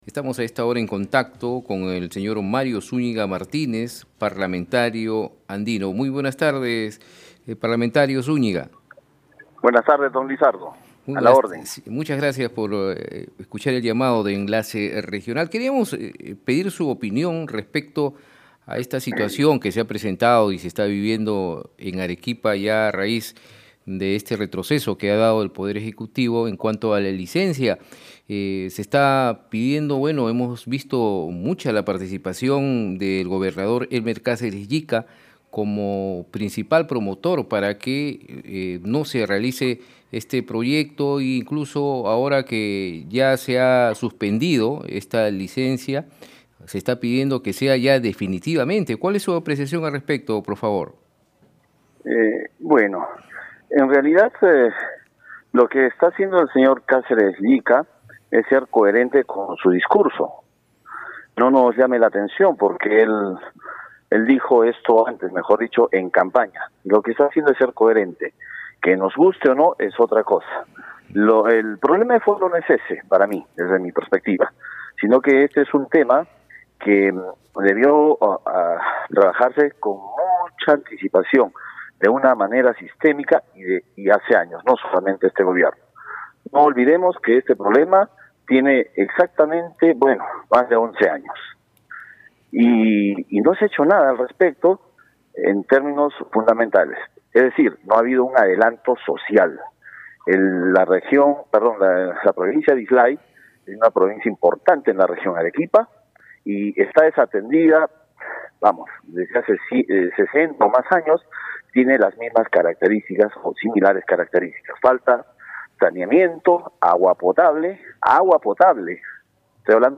ENTREVISTA-PARLAMENTARIO-ANDINO.mp3